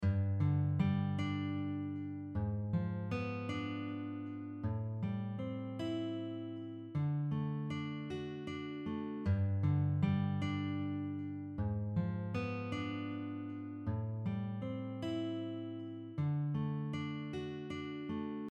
In this example, the G# dim7 is acting as a 5 chord (E7 b9) of the A minor 7 chord.
Diminished-chord-progressions-on-guitar-1.mp3